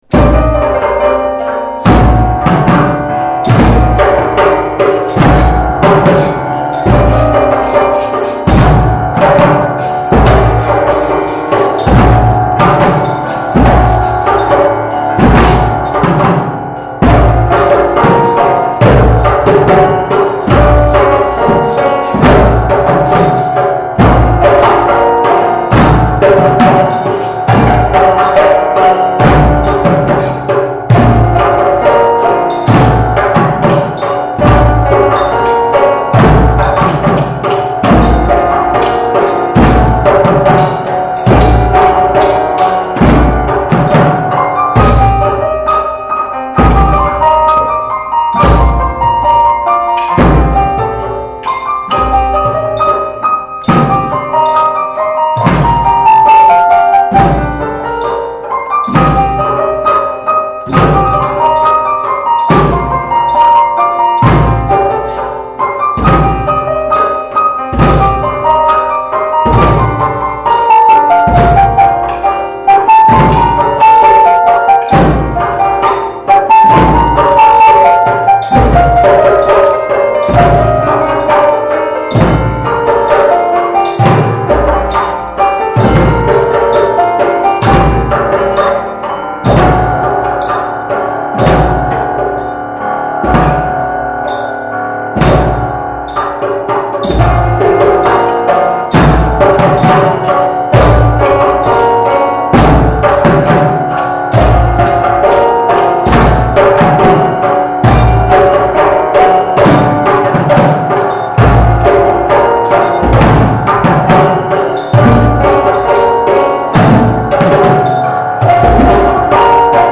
Musikalische Weltreise mit Klavierbegleitung und Orff-Orchester - Stücke aus verschiedenen Ländern und Kulturen.
Eingängige Melodien in Verbindung mit frischen harmonischen Wendungen machen die musikalische Wahrnehmung für Kinder besonders leicht und fördern ihre Konzentration, Aufnahmebereitschaft und sorgen für Entspannung.
Für dieses Projekt werden ein Klavier und verschiedene Perkussionsinstrumente benötigt.